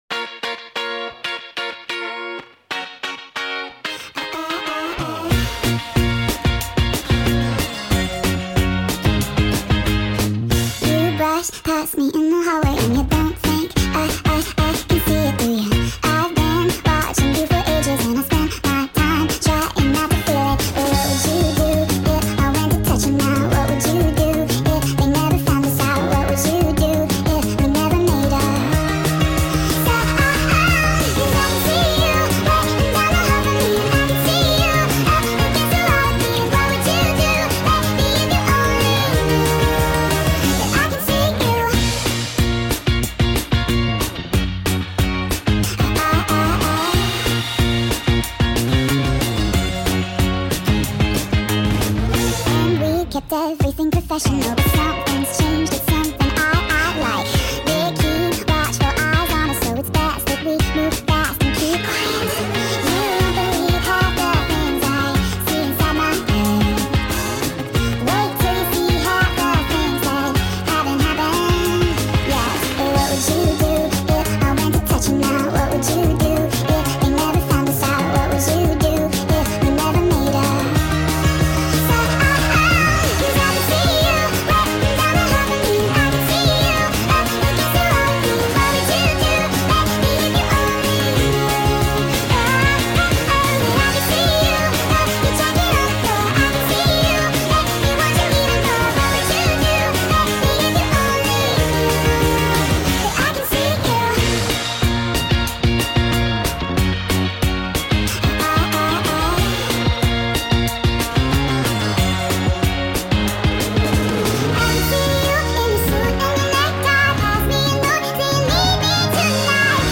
sped up !!